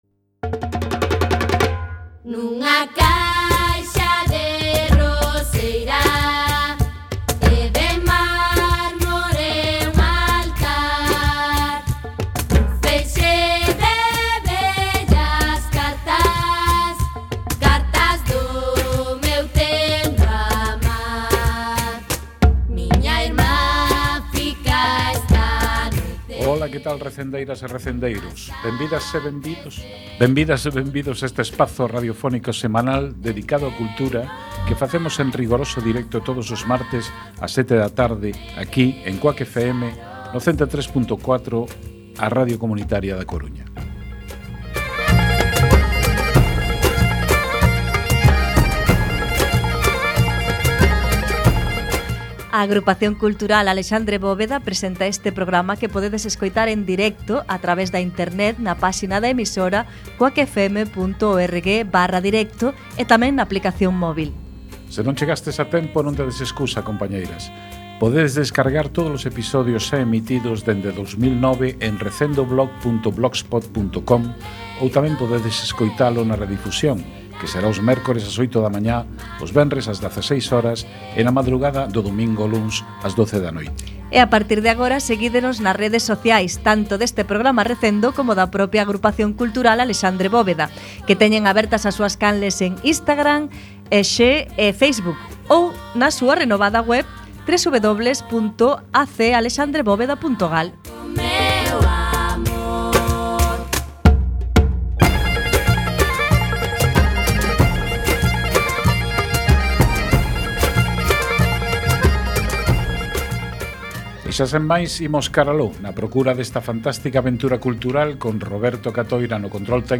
Entrevista
recitou un poema